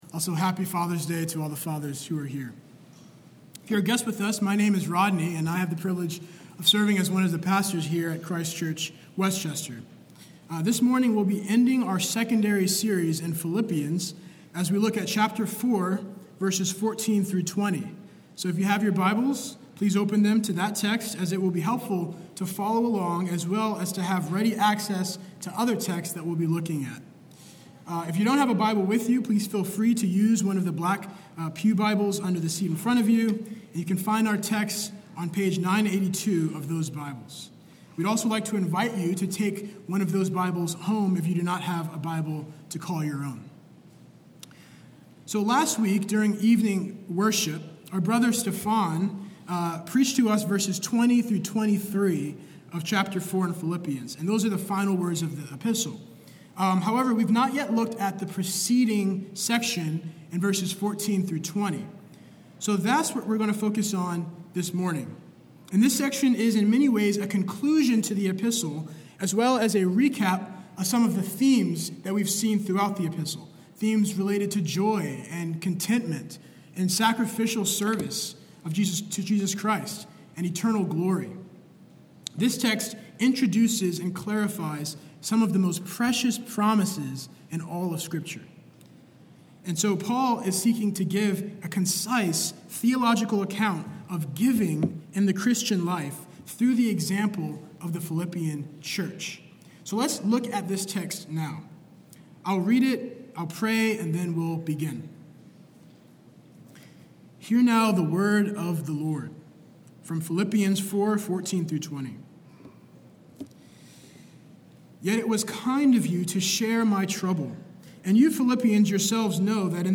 SERMON-615.mp3